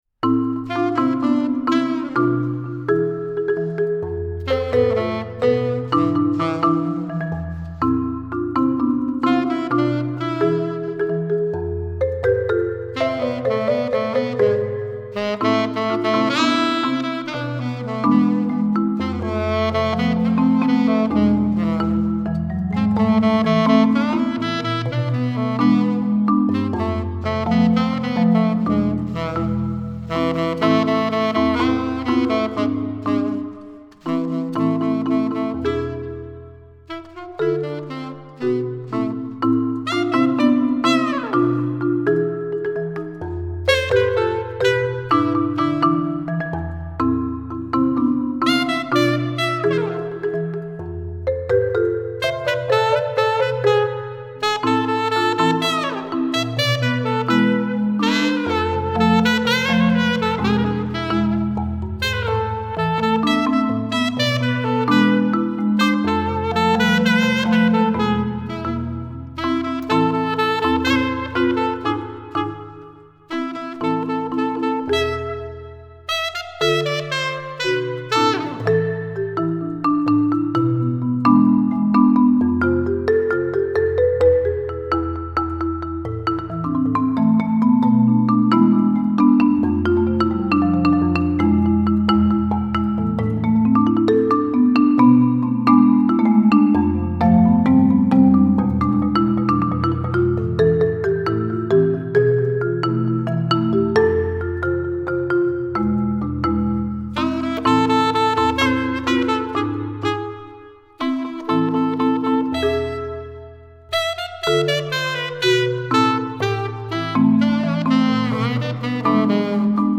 Marimba - 4 mallets , Marimba with other instruments